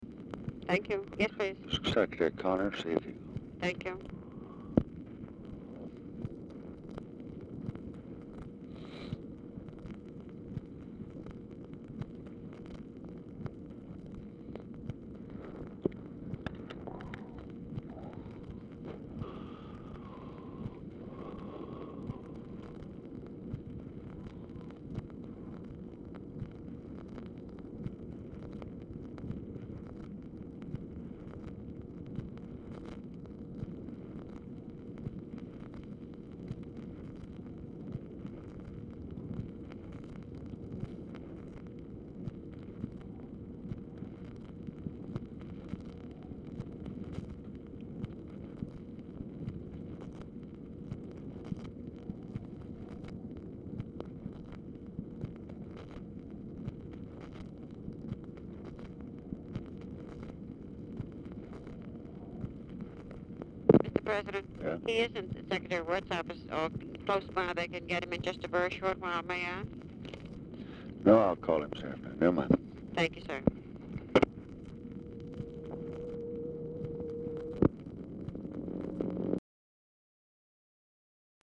Telephone conversation # 6826, sound recording, LBJ and TELEPHONE OPERATOR, 2/12/1965, time unknown
Mansion, White House, Washington, DC
LBJ ON HOLD DURING MOST OF CALL
Telephone conversation
Dictation belt